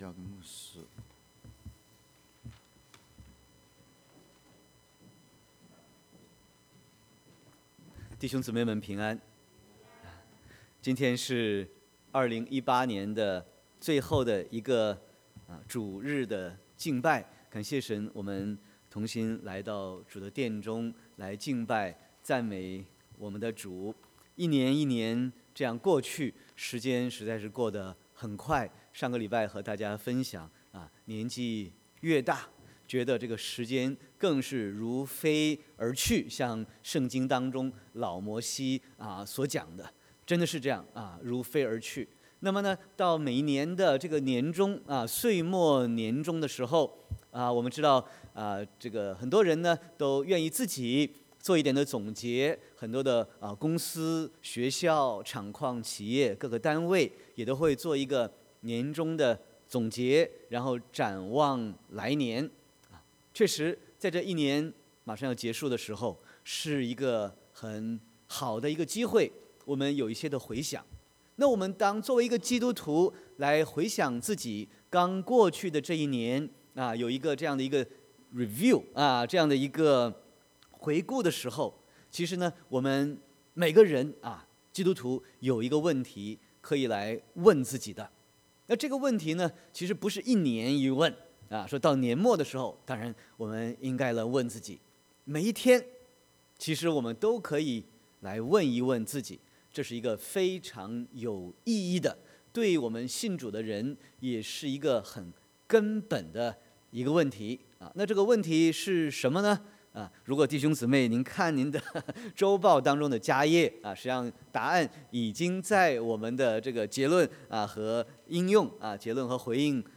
Sermon 12/30/2018